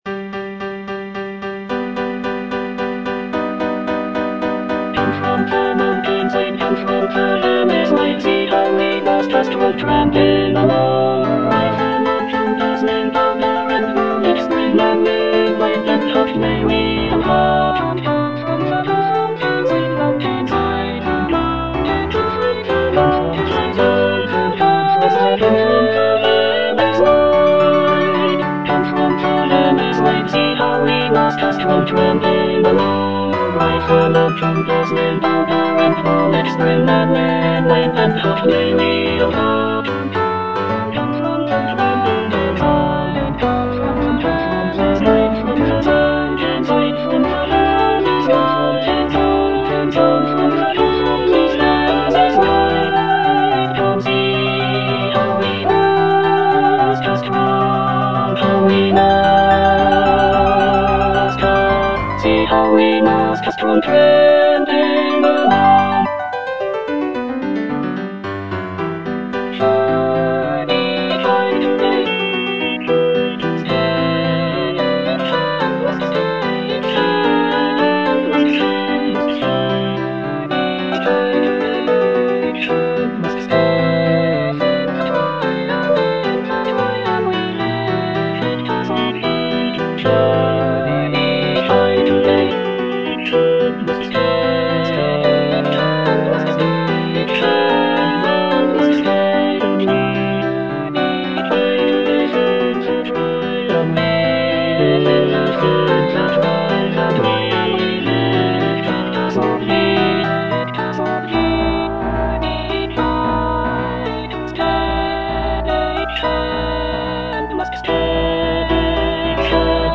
(soprano I) (All voices) Ads stop